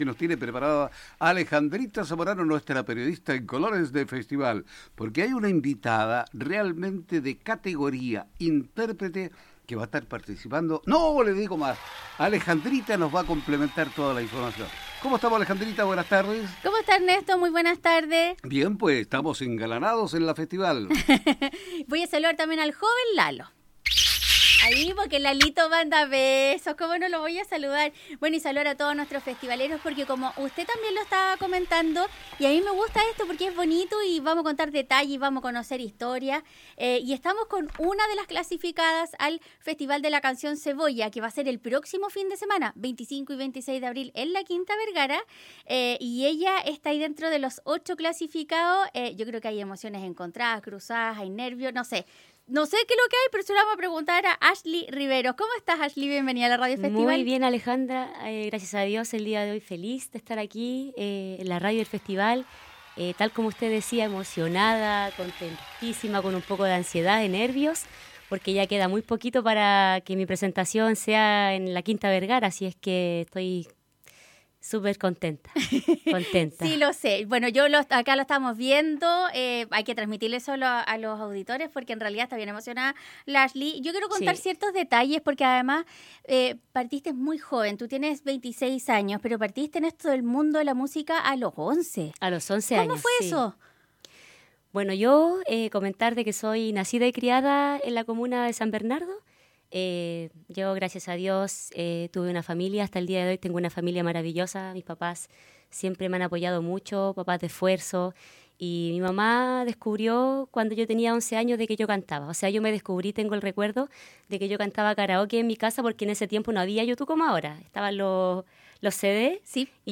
viajó para estar en los estudios de Radio Festival